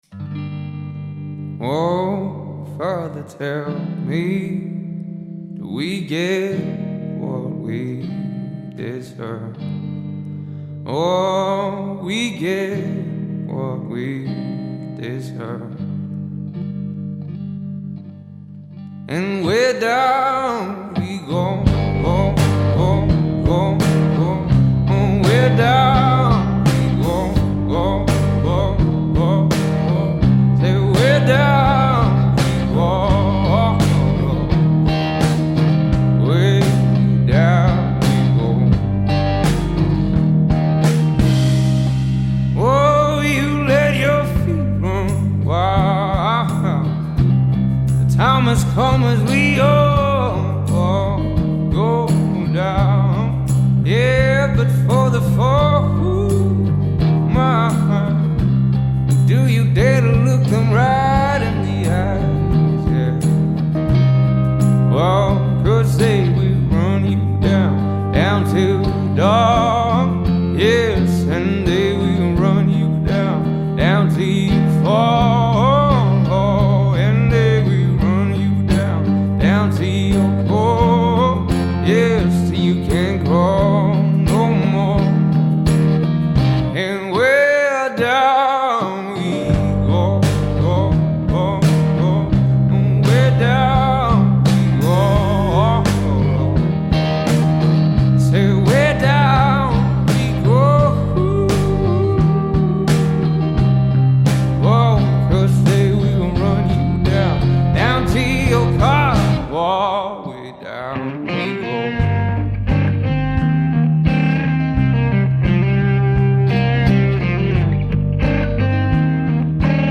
اجرای زنده